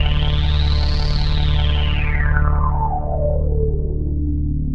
Synth 39.wav